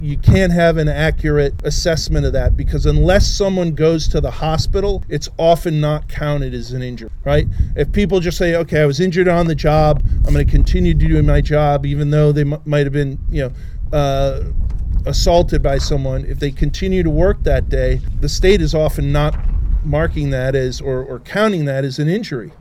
A press conference held at the entrance to North Branch Correctional Institution on McMullen Highway Thursday drew attention to safety and critical staffing shortages at state facilities.